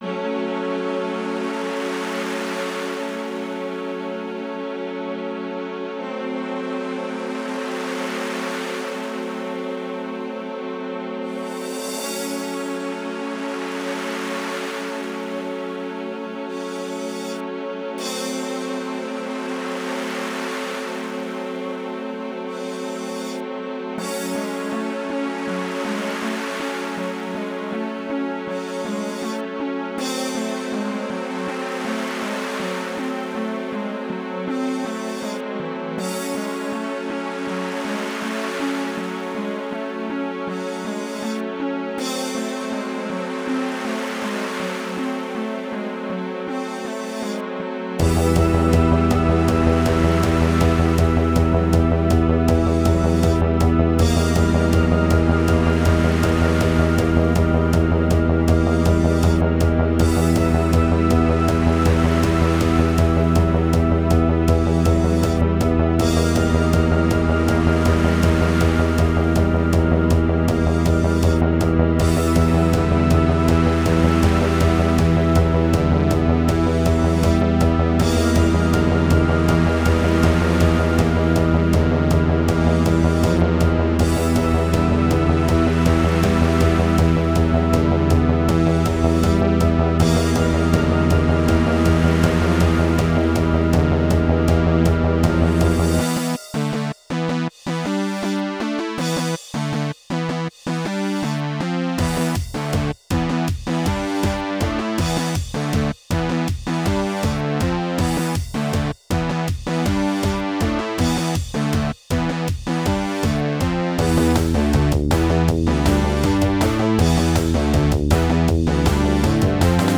A spooky tune for Halloween season! Created for the penultimate map of the upcoming Theme-gawad mapset for Doom/Freedoom.